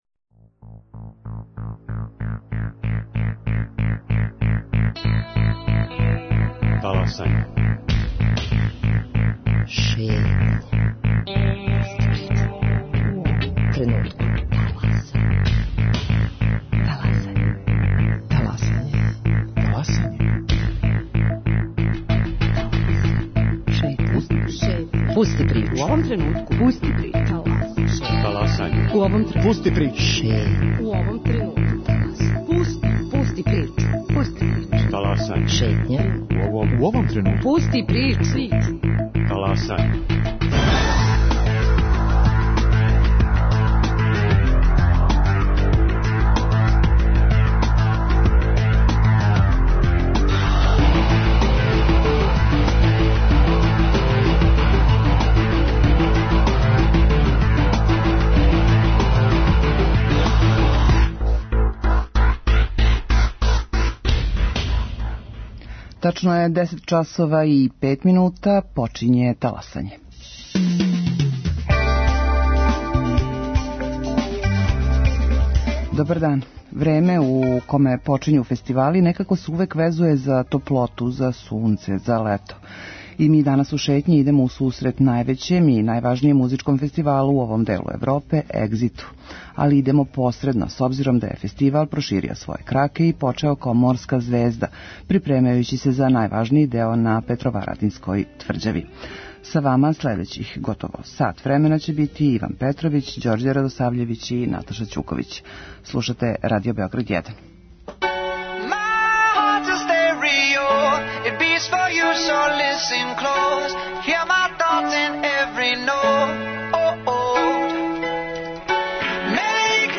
[ детаљније ] Све епизоде серијала Аудио подкаст Радио Београд 1 Ромска права у фокусу Брисела Хумористичка емисија Хумористичка емисија Хумористичка емисија Спортско вече, фудбал: Црвена Звезда - Партизан, пренос